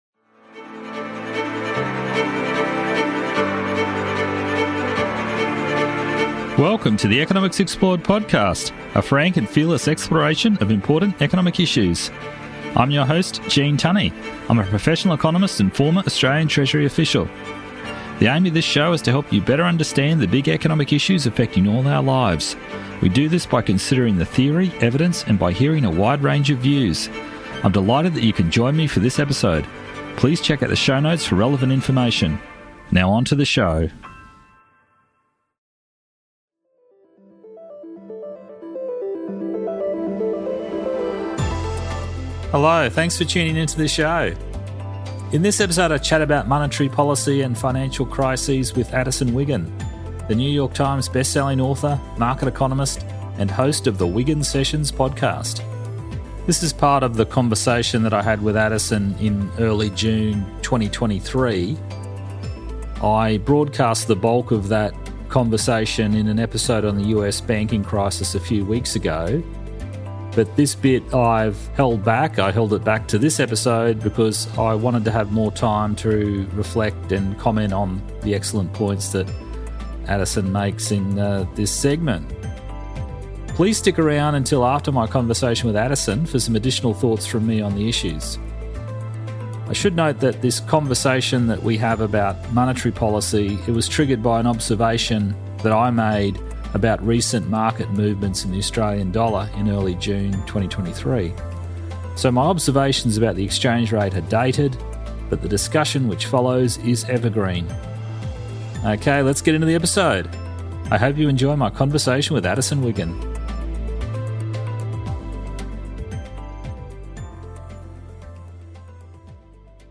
This episode features a conversation